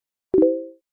this audio alert.
success.wav